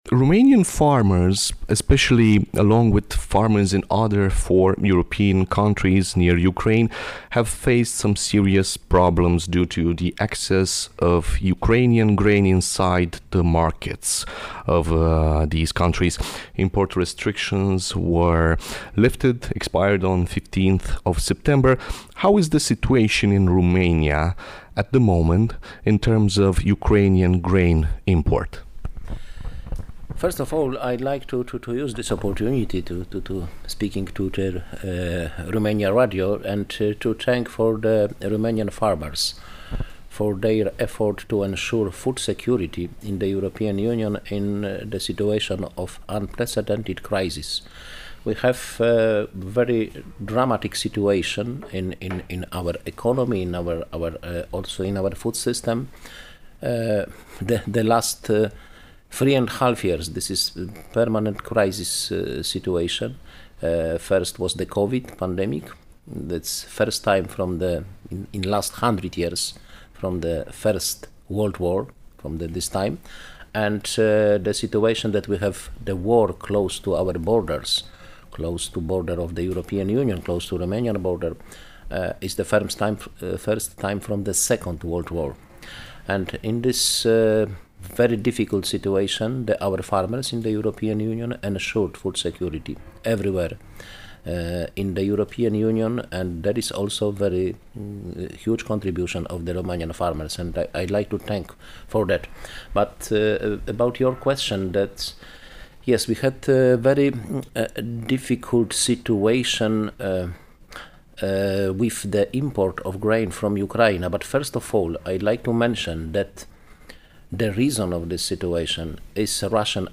Interviu-comisar-agri-BRUT.mp3